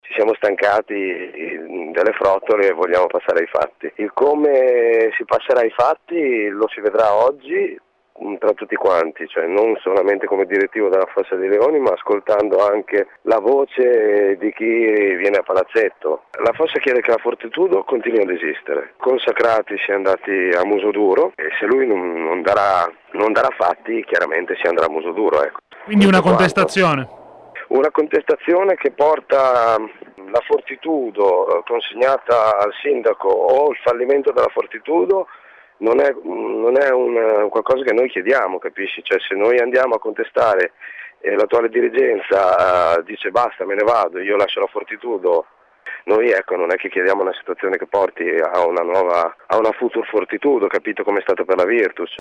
questa mattina ai nostri microfoni